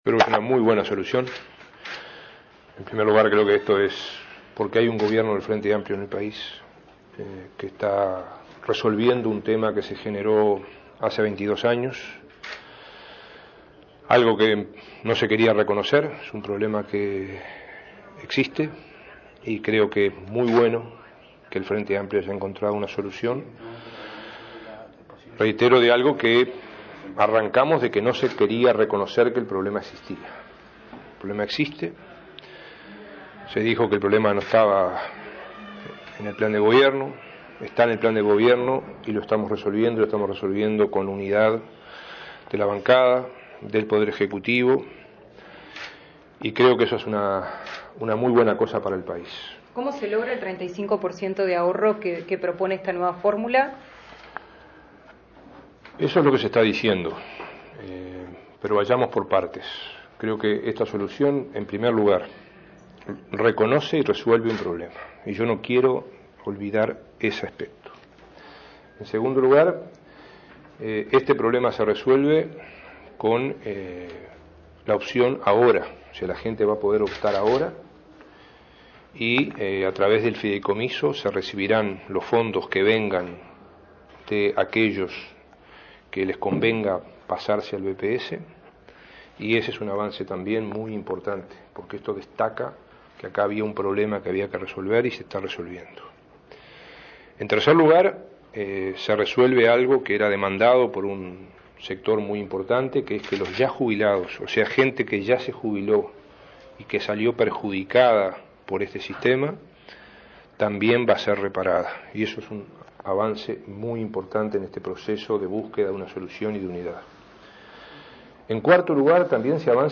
Dijo a la prensa que fue posible porque existe un gobierno del Frente Amplio que está resolviendo un problema generado hace 22 años. La fórmula permitirá pasar de la AFAP al BPS y soluciona el problema a quienes ya están jubilados.